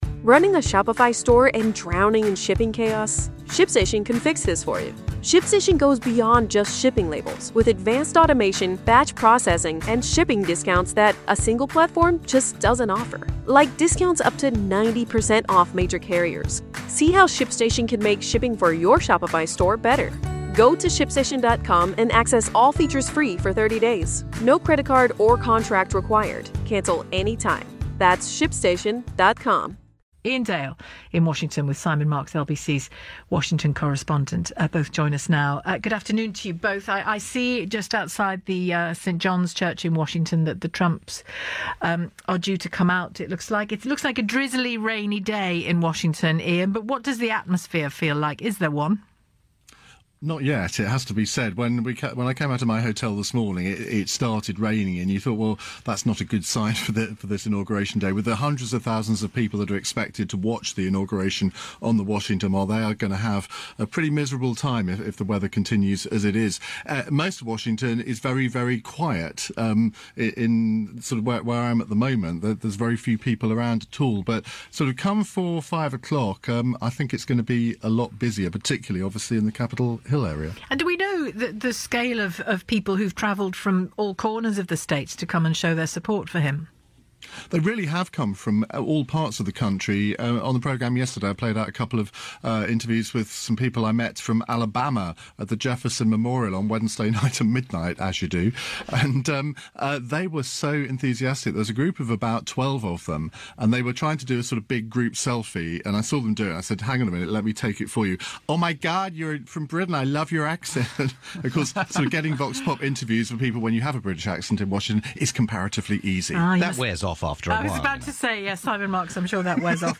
previewed the inauguration from FSN's studios in Washington DC.